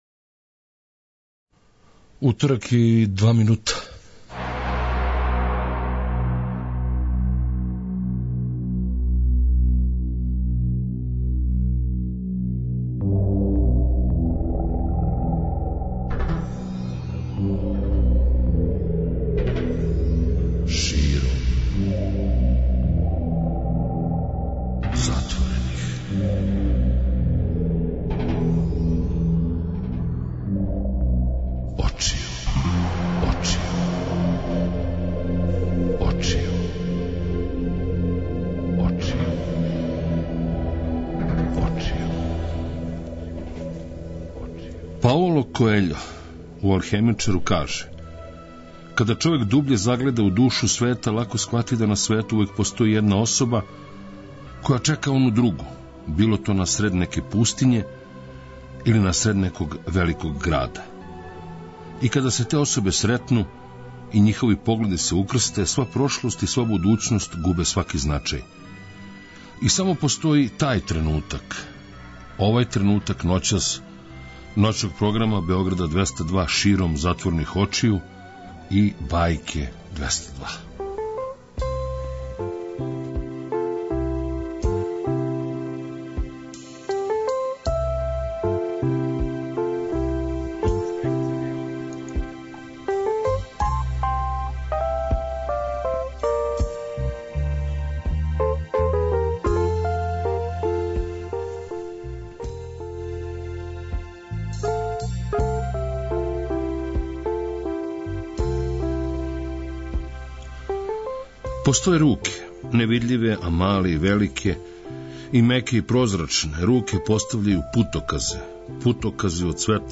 Направићемо једну музичку Бајку која ће обиловати темама из филмова, серија, а можда напишемо заједно неку Бајку ове ноћи.